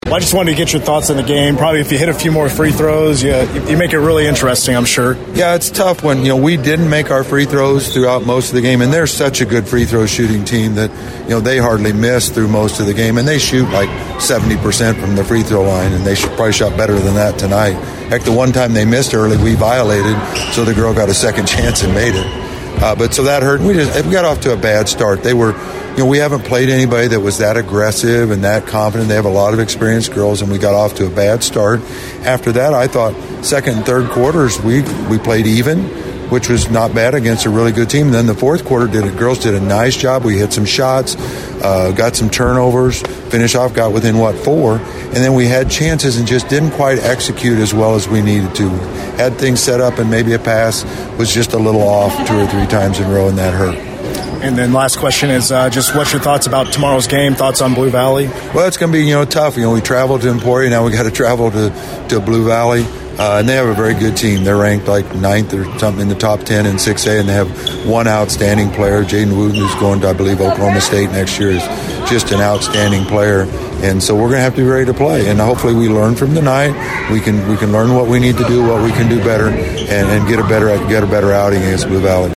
Postgame thoughts